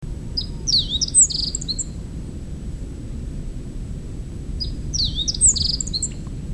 Песня красноухой овсянки
Пение самца красноухой овсянки.
SDR_0052_Emberiza_cioides_male-song.mp3